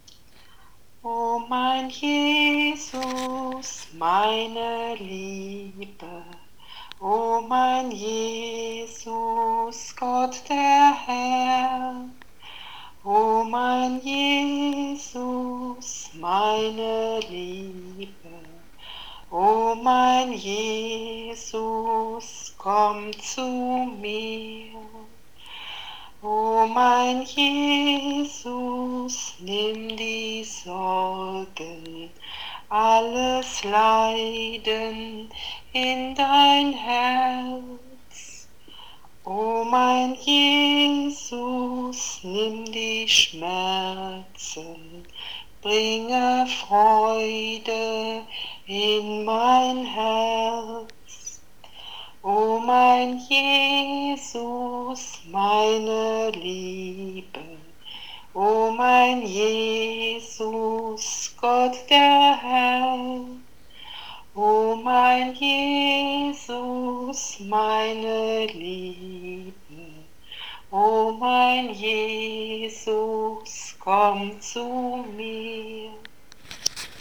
Tu je spievaná pieseň!